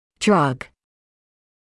[drʌg][драг]лекарственный препарат; наркотик